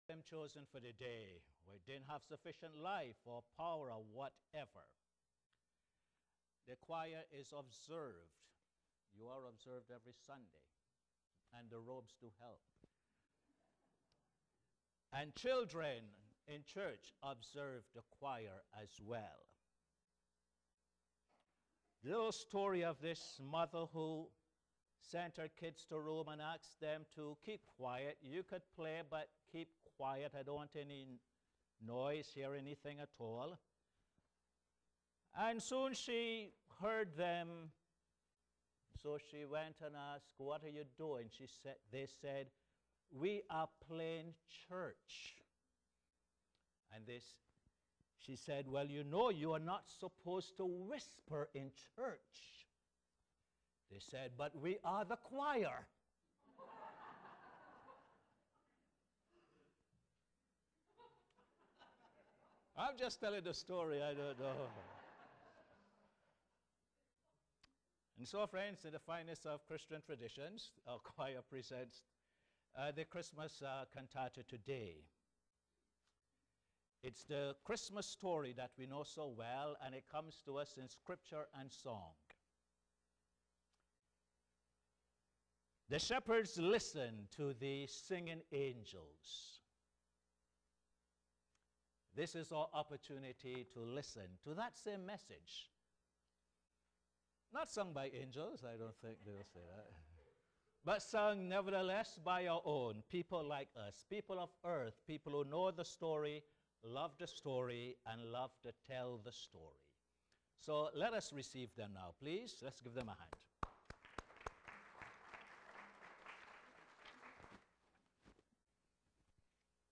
Posted in Sermons on 22. Dec, 2011